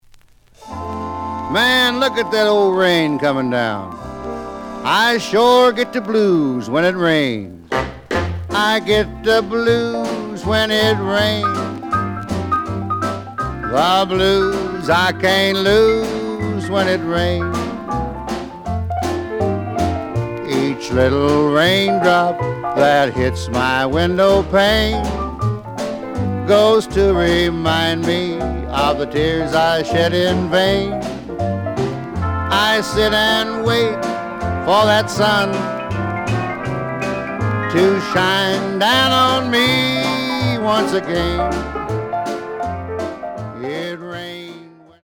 The audio sample is recorded from the actual item.
●Format: 7 inch
●Genre: Vocal Jazz